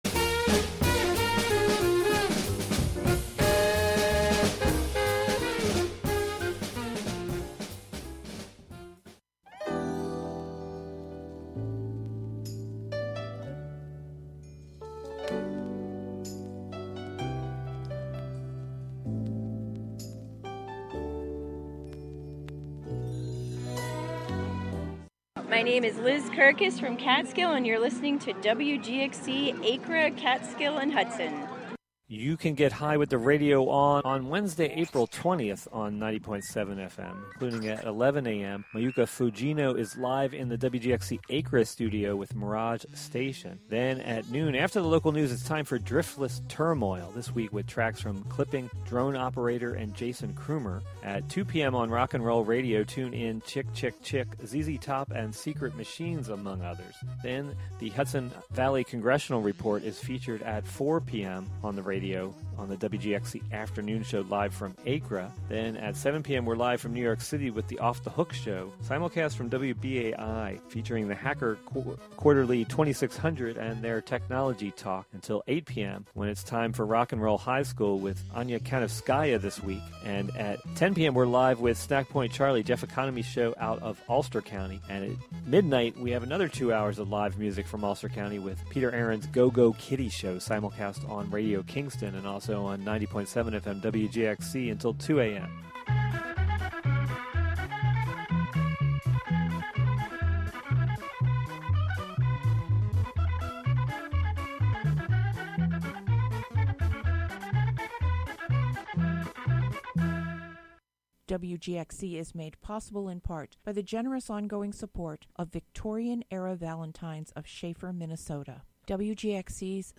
Inspired by spring, the broadcast will feature the sounds of the season, including music, field recordings, poetry, and more.
The show features music, field recordings, performances, and interviews, primarily with people in and around the Catskill Mountains of New York live from WGXC's Acra studio.